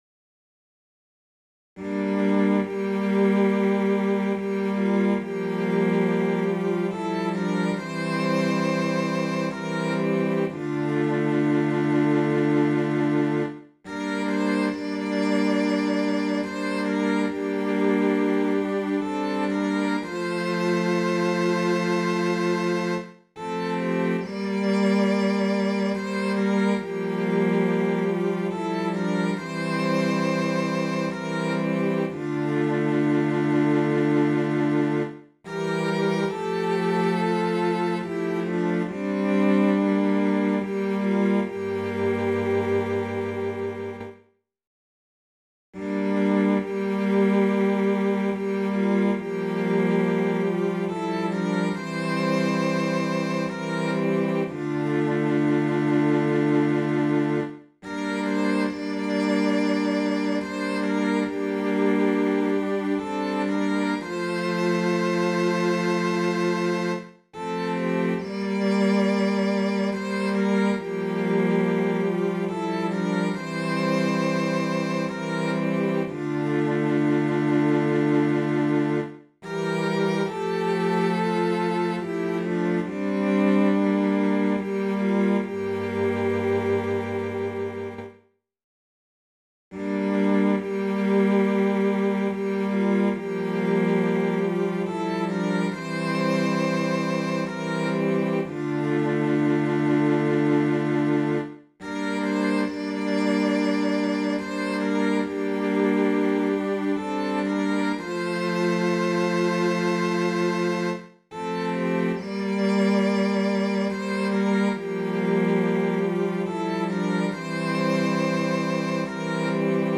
Voicing/Instrumentation: SATB We also have other 1 arrangement of " Alas! and Did My Saviour Bleed ".